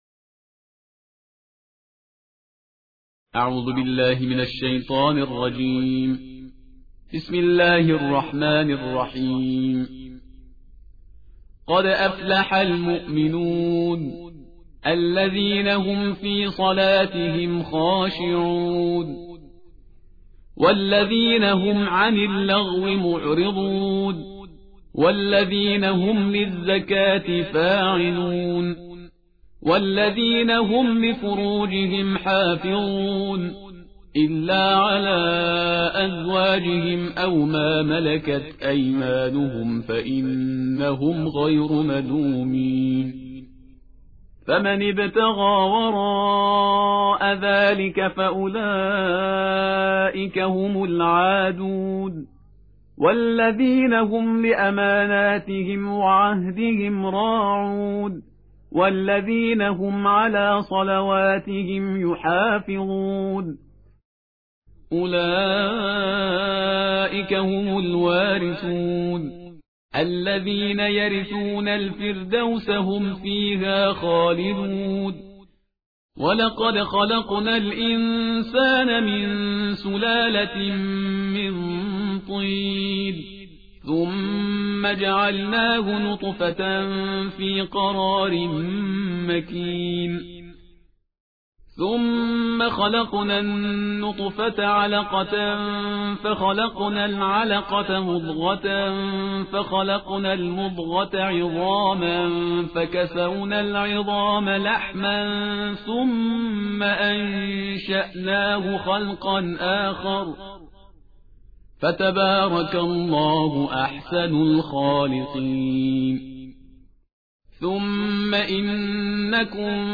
ترتیل جزءهجده قرآن کریم/استاد پرهیزگار
ترتیل جزءهجده ماه مبارک رمضان/استاد پرهیزگار